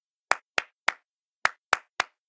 colt_emo_clap_01.ogg